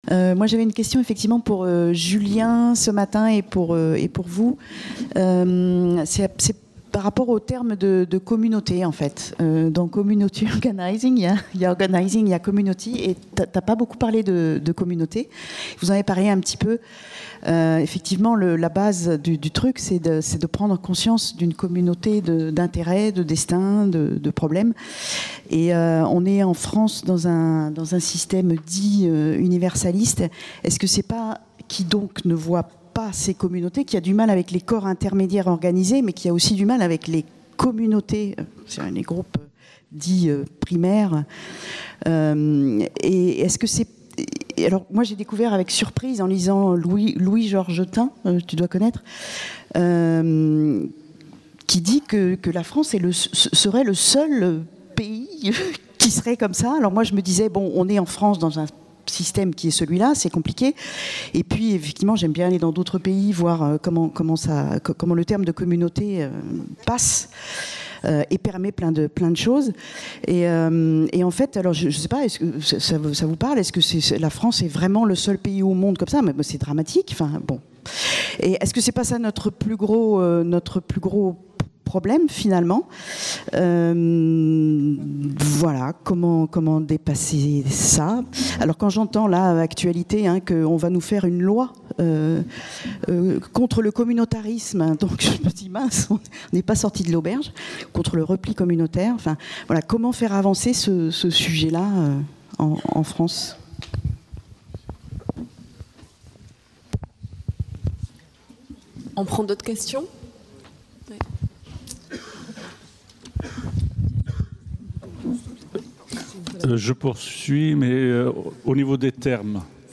22- (Table ronde 4) : Débat avec le public (6) | Canal U